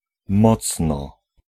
Ääntäminen
IPA: [ˈmɔt͡s̪n̪ɔ]